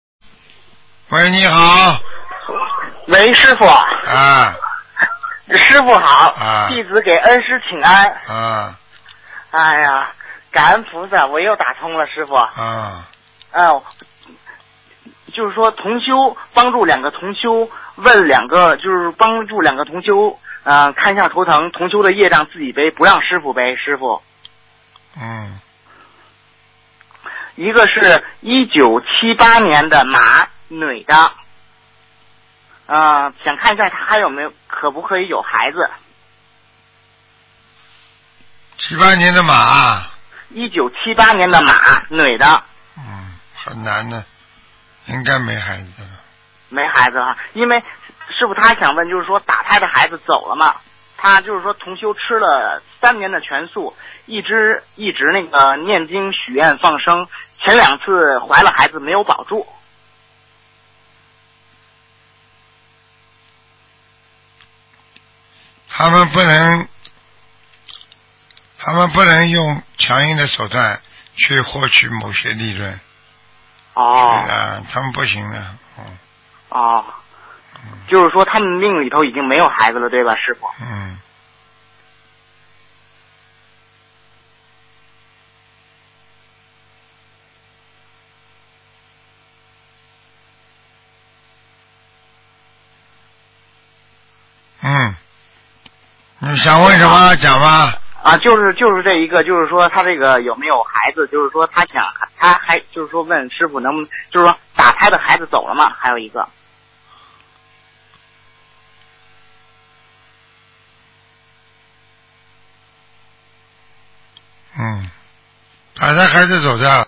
目录：2015年剪辑电台音频集锦